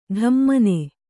♪ ḍhammane